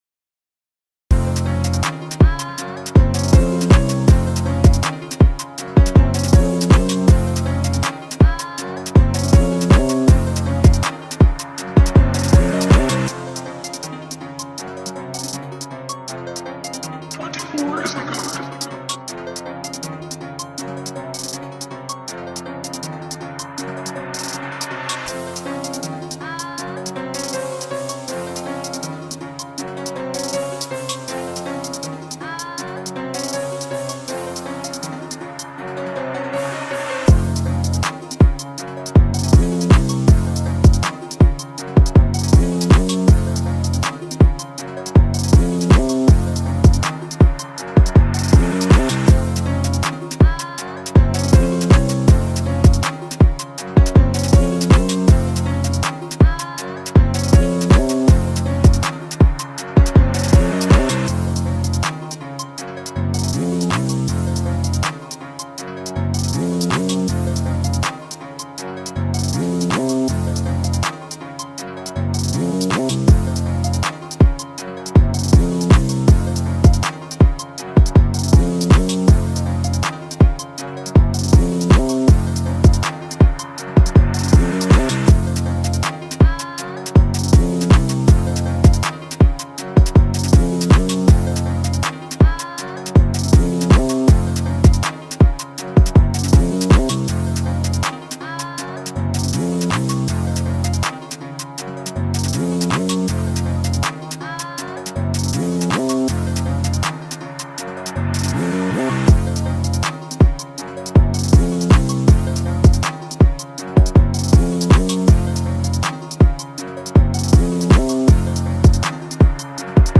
official instrumental
2021 in NY Drill Instrumentals